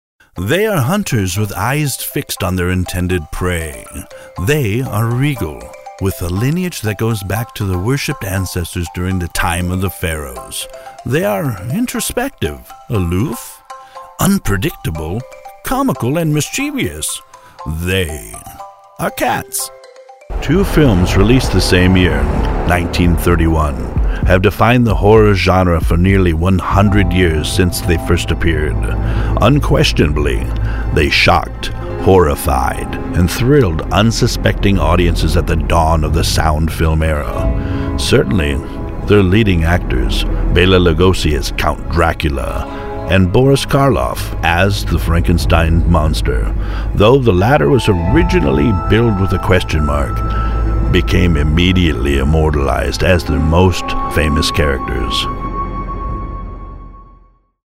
Male
English (North American)
Adult (30-50)
Narration
Narration For Any Application
All our voice actors have professional broadcast quality recording studios.